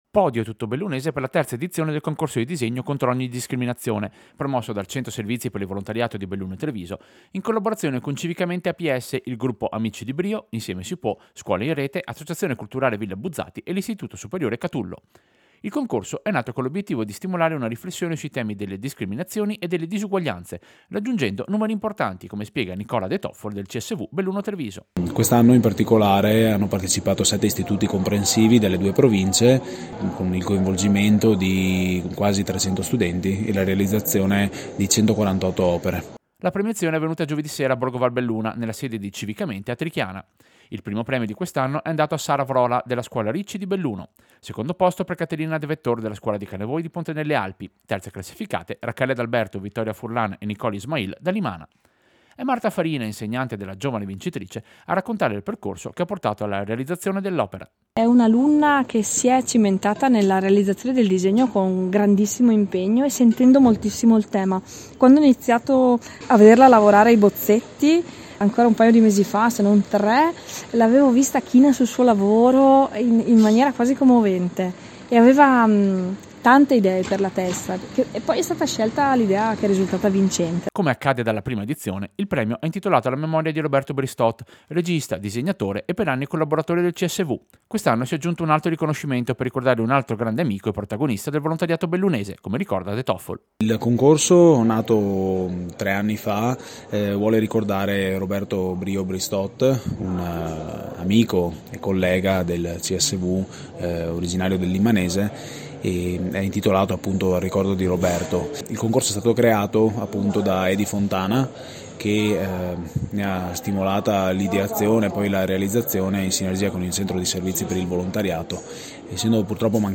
Servizio-Premio-scuola-lotta-discriminazioni-CSV.mp3